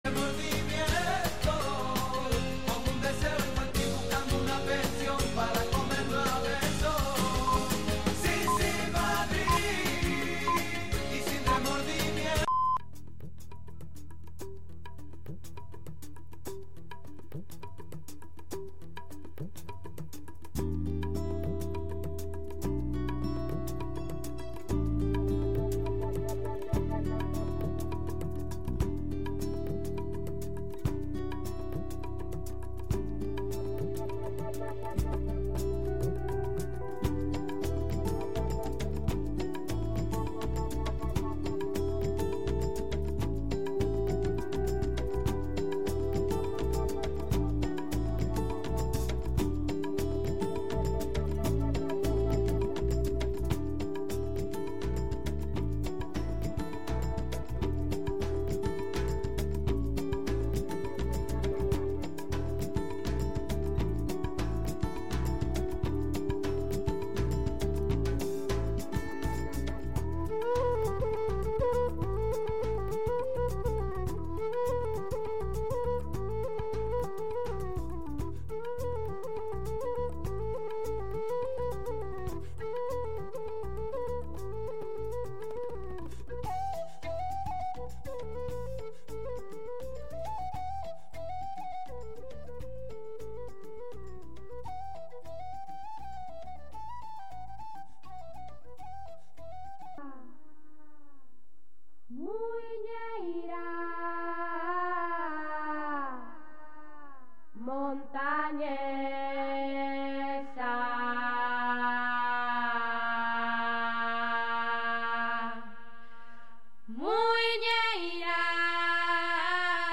Recorreguts musicals pels racons del planeta, música amb arrels i de fusió. Sense prejudicis i amb eclecticisme.